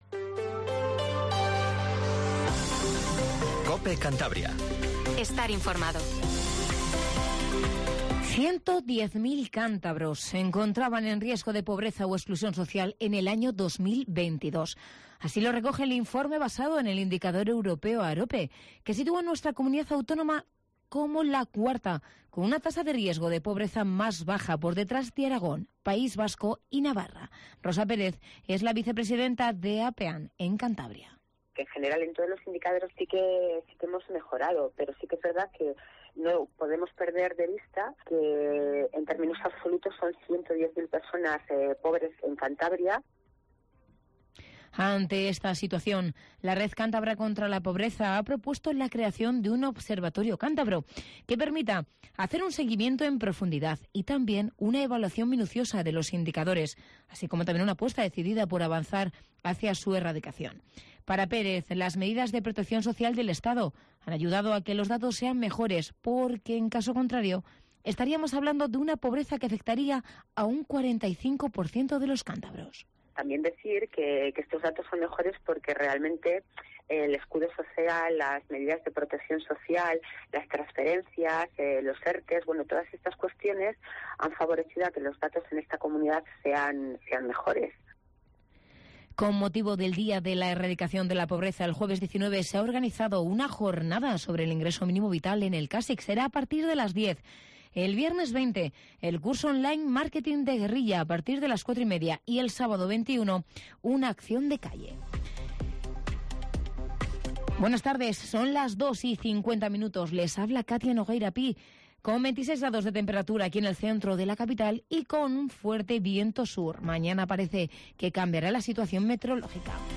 Informativo Regional 14:48